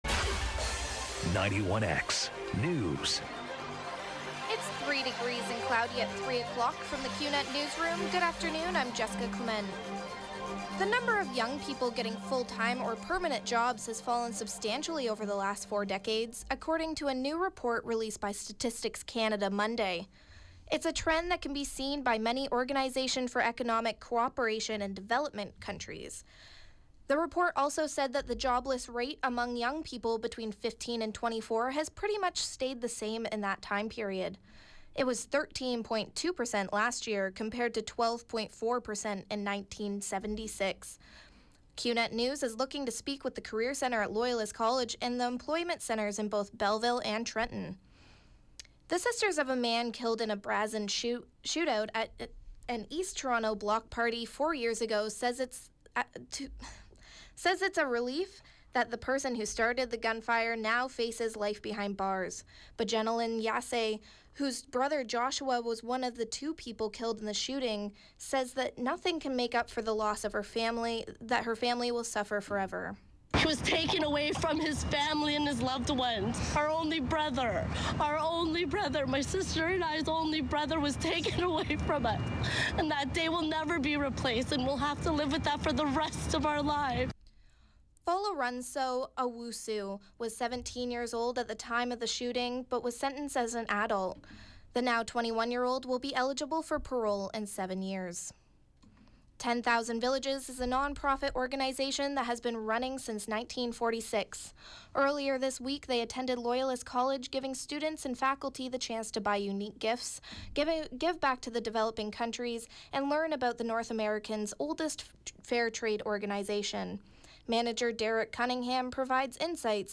91X Newscast – Tuesday, Dec. 7, 2016, 3 p.m.